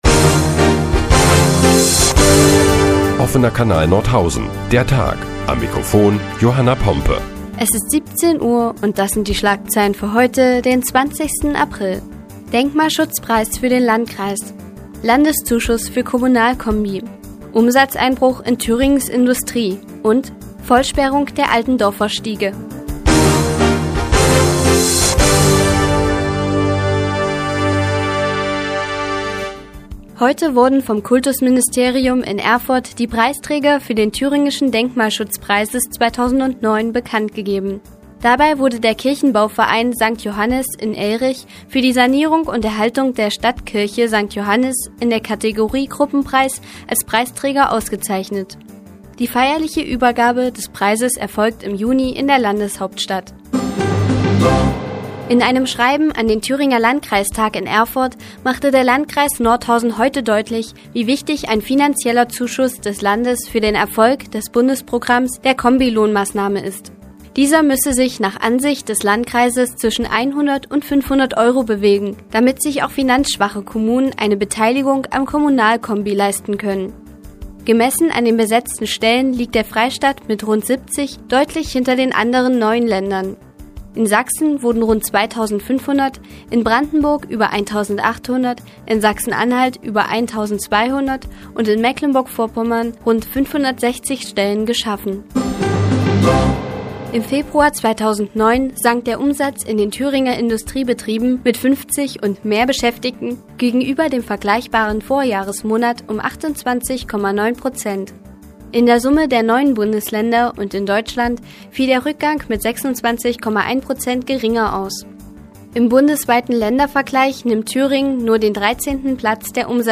Die tägliche Nachrichtensendung des OKN ist nun auch in der nnz zu hören. Heute geht es unter anderem um den Umsatzeinbruch in Thüringens Industrie und den Denkmalschutzpreis.